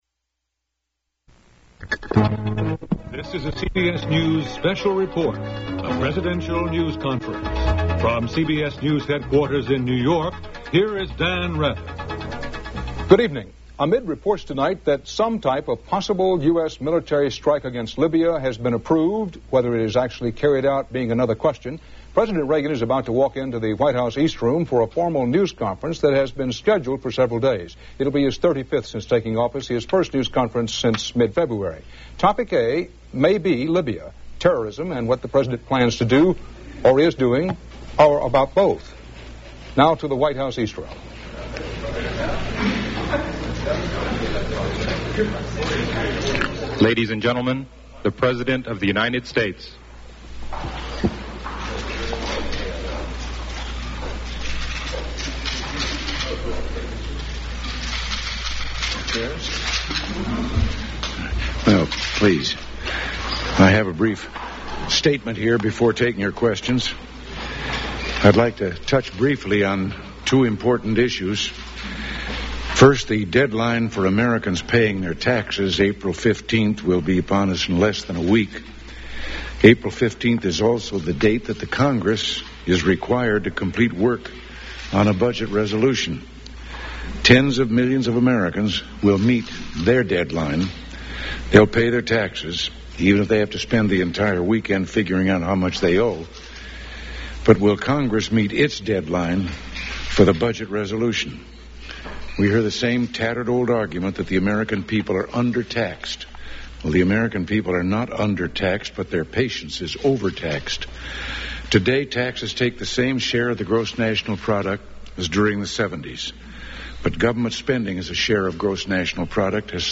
Press conference opens with a statement on the federal budget. Other topics discussed were U.S. participation in Central American internal politics, the possibilty of further military engagemnts in Libya, Muammar Qaddafi, and a June summit talk with Gorbachev. A woman asks Reagan to come to Canada to receive an award from an international bodybuilders association.